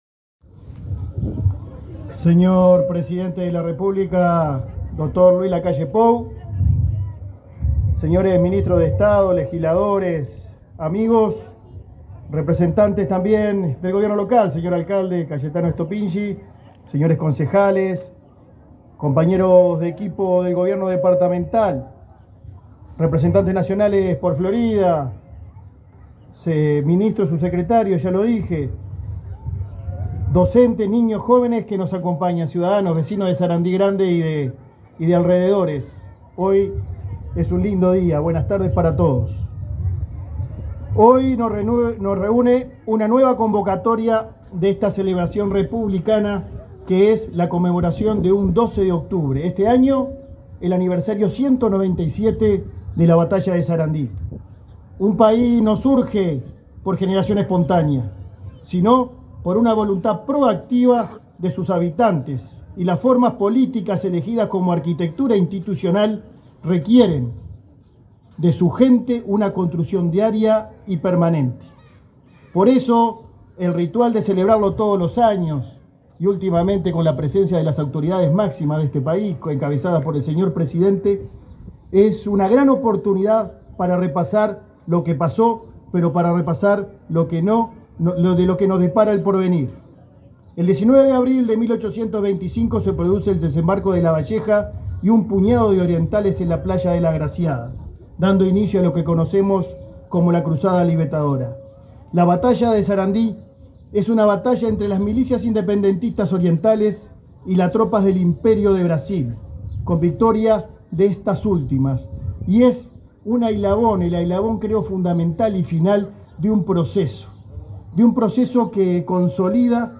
Conferencia de prensa por el 197.° aniversario de la Batalla de Sarandí
Conferencia de prensa por el 197.° aniversario de la Batalla de Sarandí 12/10/2022 Compartir Facebook X Copiar enlace WhatsApp LinkedIn Con la presencia del presidente de la República, Luis Lacalle Pou, se realizaron, este 12 de octubre, los actos recordatorios de un nuevo aniversario de la Batalla de Sarandí, en Sarandí Grande, Florida.